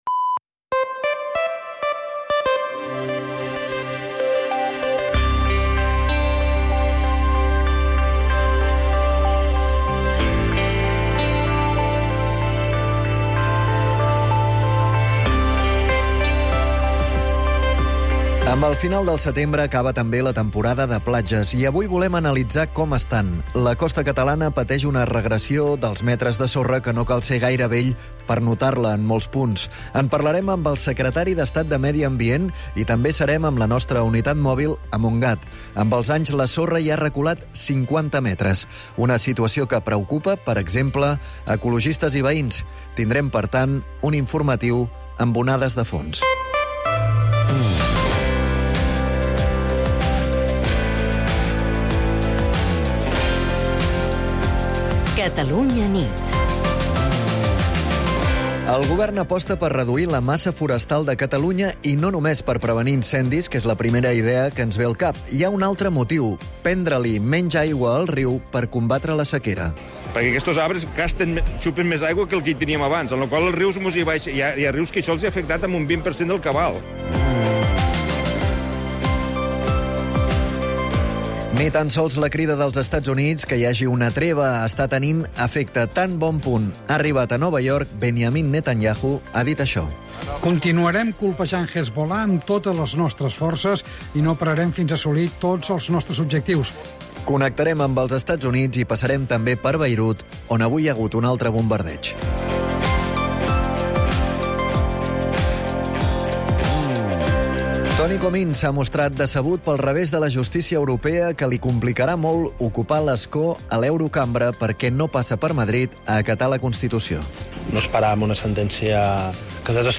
Convidem el secretari d'Estat de Medi Ambient, Hugo Morn.